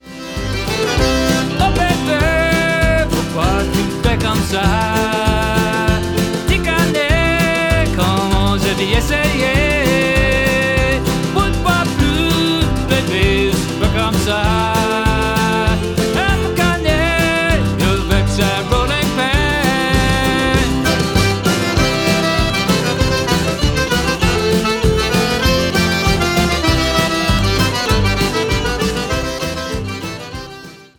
fiddle, washboard & vocals
accordion fiddle & vocals
guitar
bass
drums & percussion
Cajun/zydeco group
The warm, live-sounding production